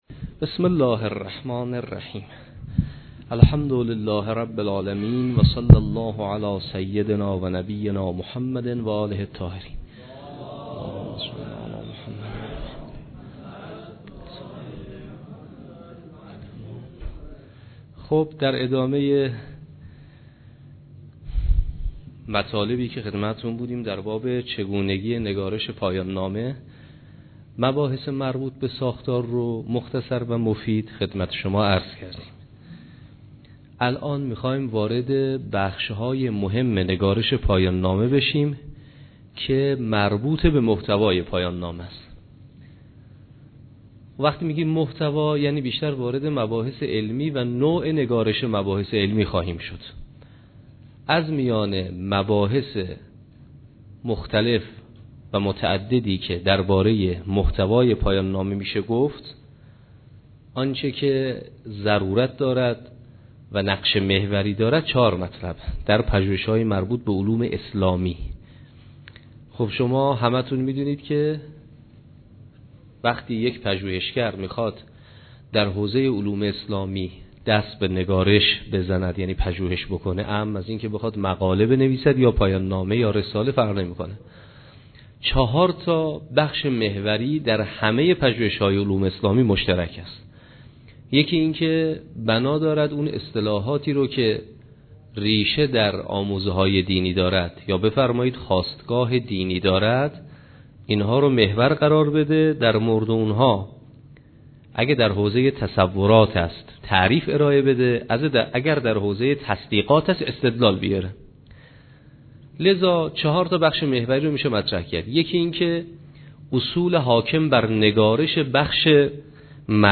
جلسه سوم کارگاه پایان نامه نویسی